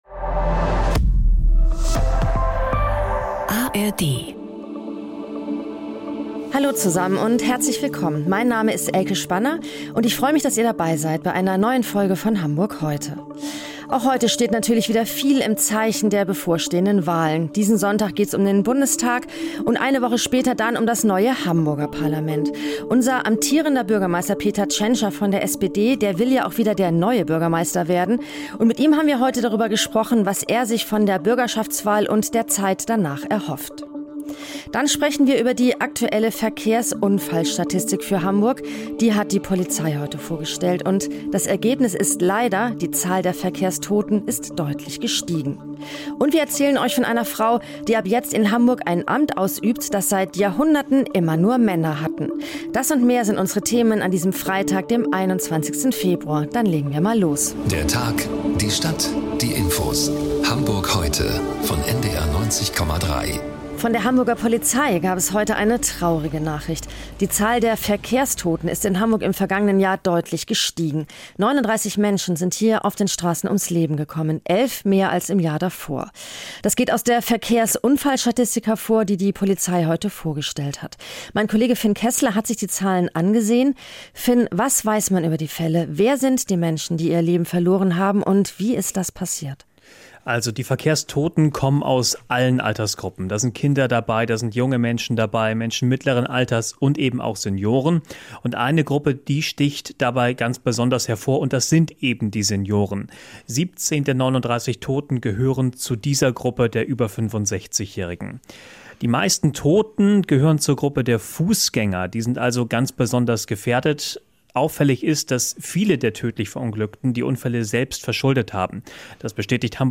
Unsere Reporterinnen und Reporter sind für Sie sowohl nördlich als auch südlich der Elbe unterwegs interviewen Menschen aus Wirtschaft, Gesellschaft, Politik, Sport und Kultur.
… continue reading 494 епізодів # NDR 90,3 # NDR 90 # Tägliche Nachrichten # Nachrichten # St Pauli